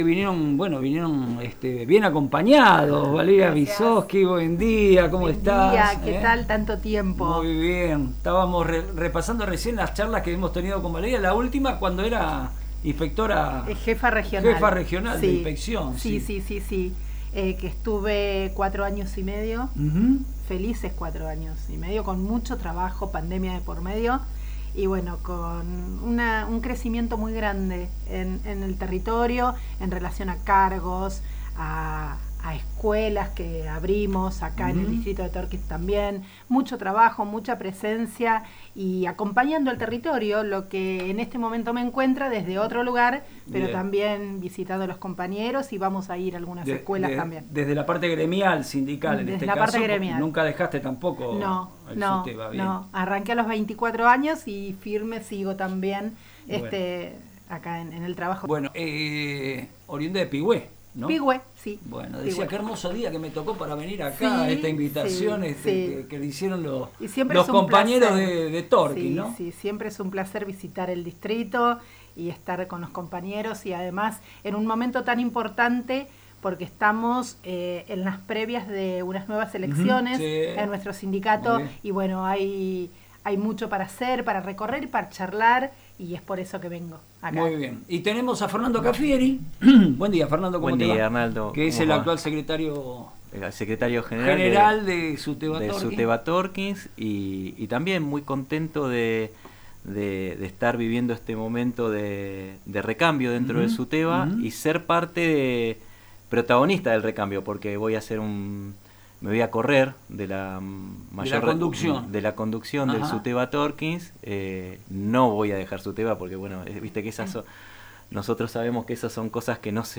Durante la presentación de esta mañana en FM Reflejos, los referentes gremiales alertaron sobre la gravedad de las amenazas de violencia en las escuelas secundarias y destacaron el rol de la educación en la construcción de la memoria.